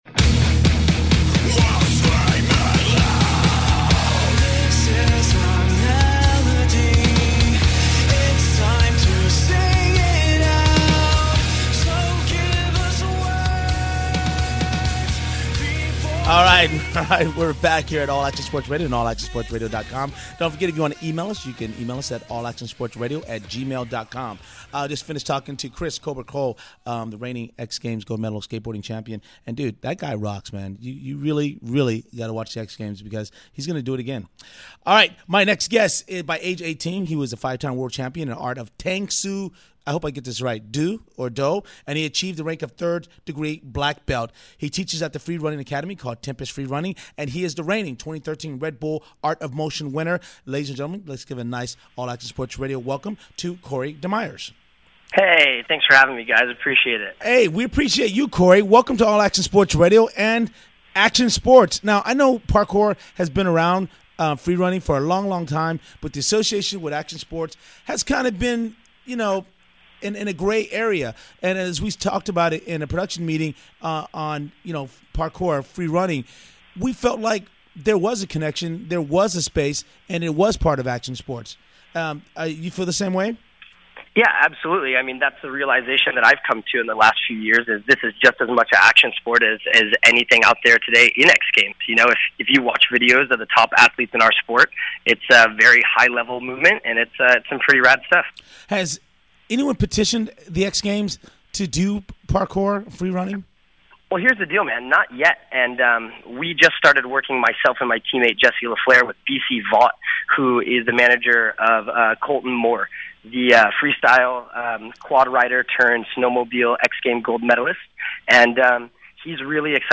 AASR Athlete Interview